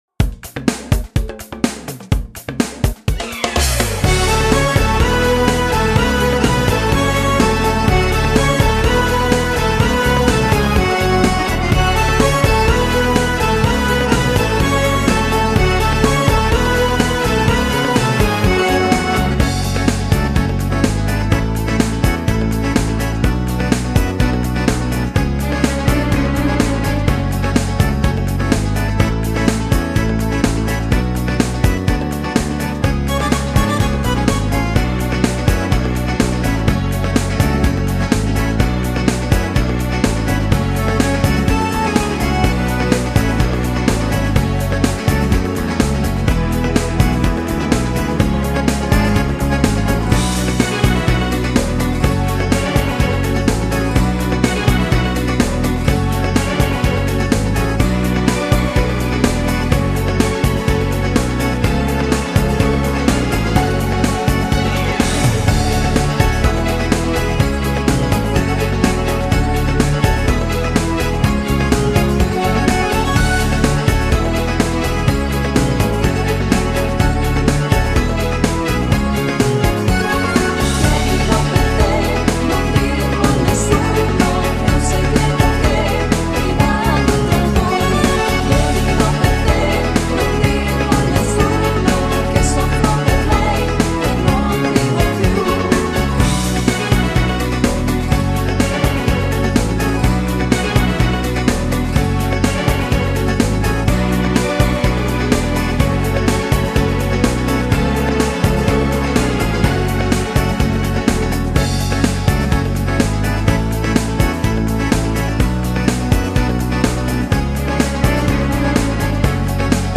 Genere: Beguine moderna
Scarica la Base Mp3 (3,50 MB)